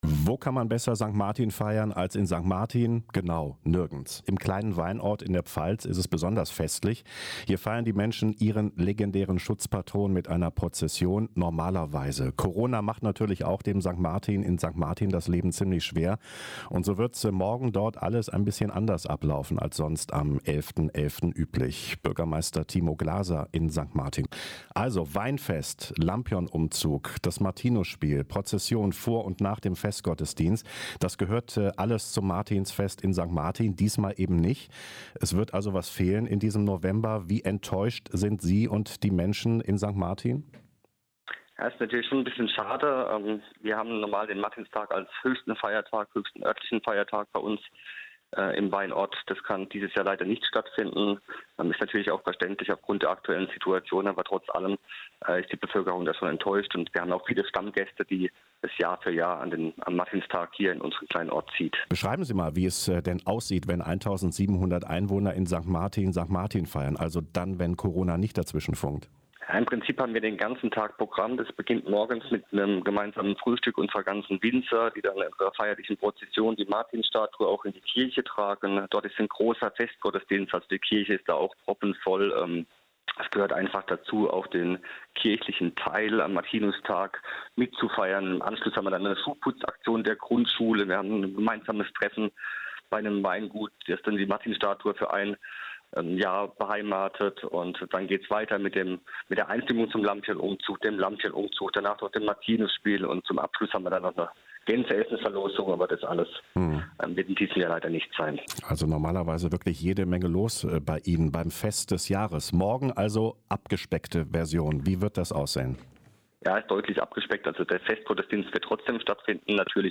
Ein Interview mit Timo Glaser (Ortsbürgermeister von St. Martin)